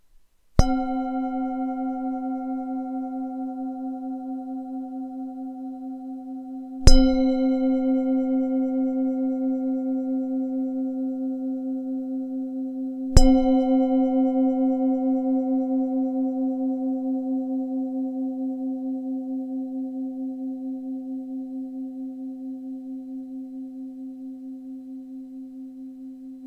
Lesklá tibetská mísa C3,B3 16,5cm
Nahrávka mísy úderovou paličkou: